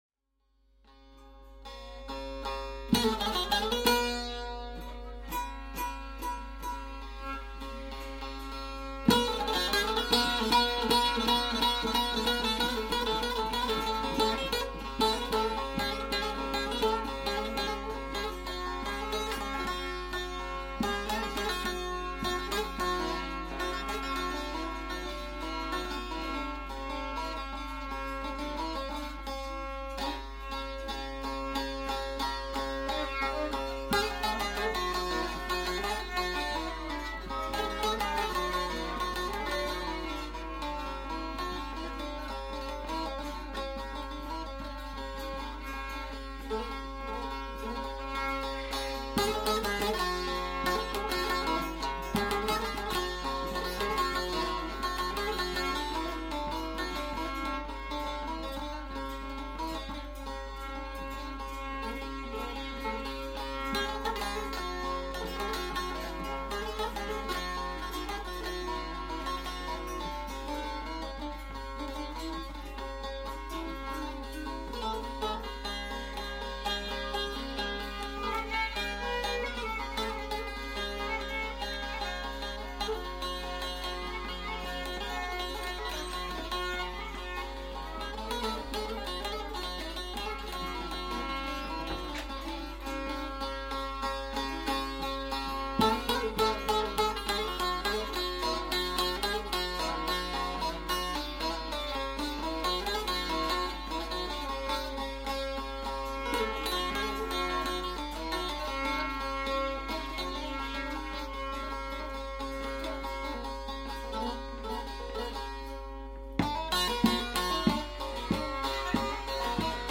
üçtelli bağlama & voice
düdük
bağlama & voice
violin
recorded at his home
Çameli, Turkey